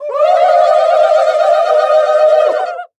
Звук с боевым кличем индейцев перед атакой